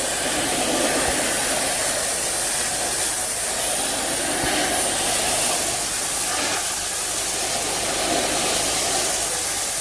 Hose_Audio_B.wav